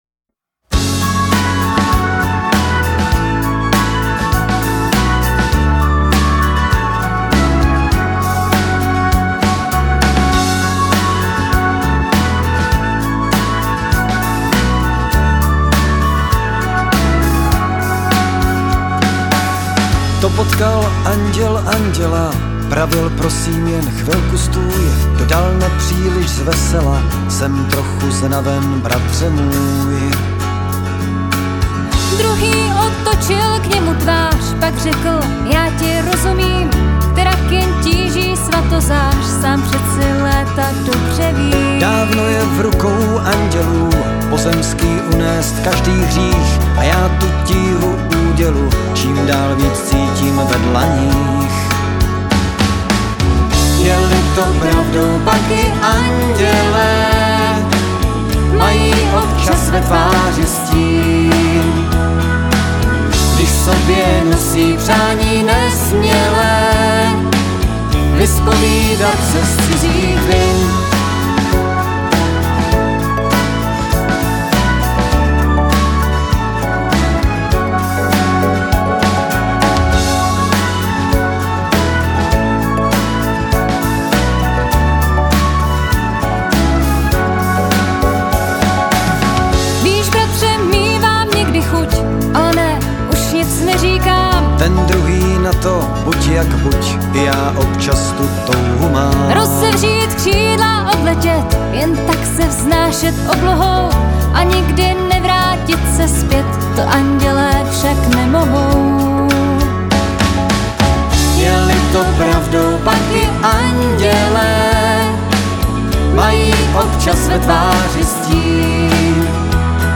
originálních písničkách